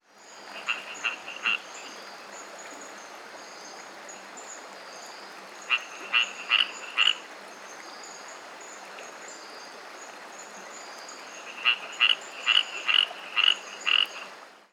Pug-nosed Treefrog
These frogs were recorded calling from rocks alongside a stream in a premontane forest.
The call of this species is a coarse quacking sound.  It is somewhat remniscent of the larger Smilisca species, although not quite as loud nor complex as their calls.
Pug-nosed Treefrog from Anton Valley, Panama
The calls seem to change as they are repeated.
The first calls are very short compressed "quacks" but later calls are slowed down into longer rattling sounds.